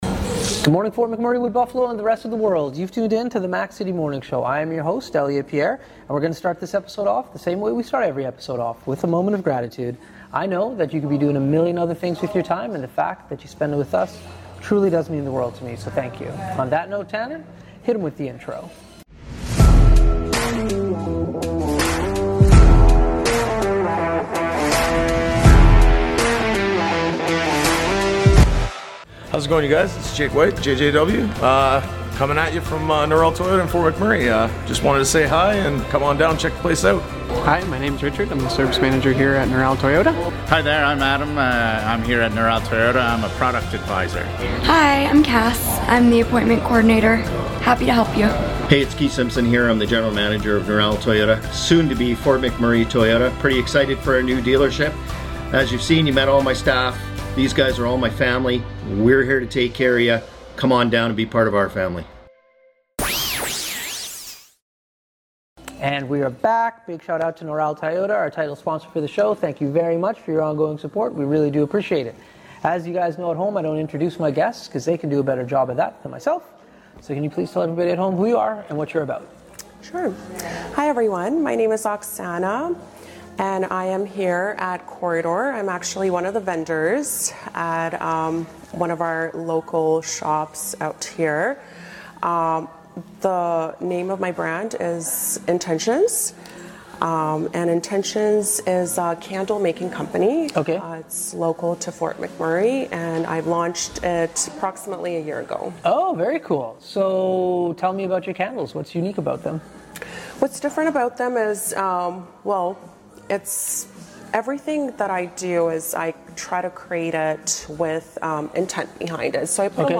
We are back on location at Corridor with the&nbsp